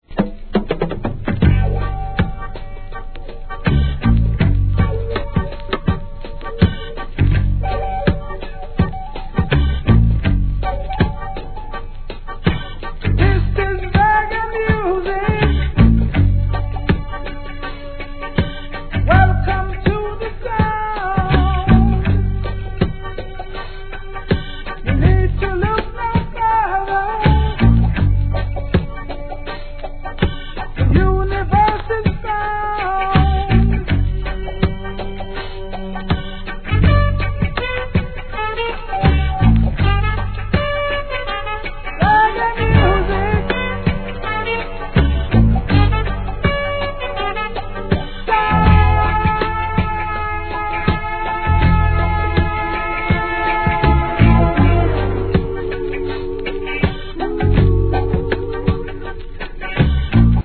REGGAE
ムーディーなINST.物からルーディーな怒渋作品まで堪能できます!!